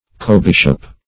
Cobishop \Co`bish"op\, n. A joint or coadjutant bishop.